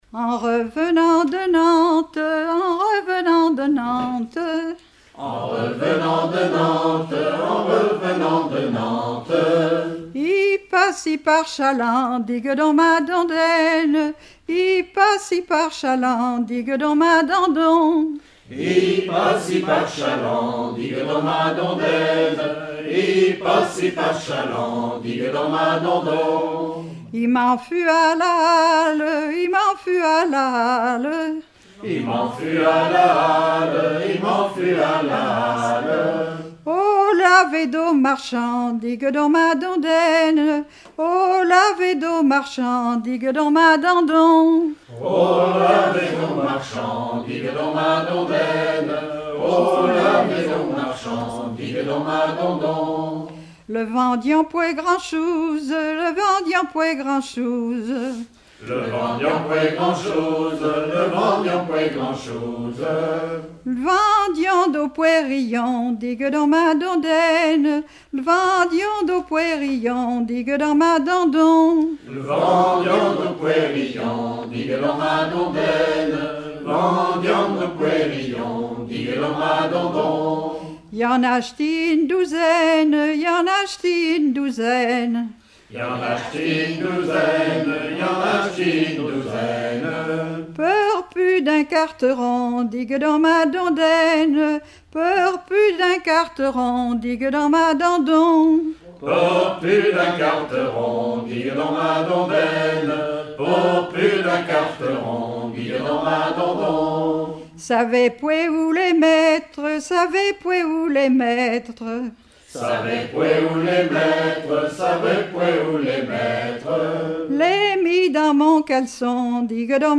Patois local
Genre laisse
enregistrement d'un collectif lors d'un regroupement cantonal
Pièce musicale inédite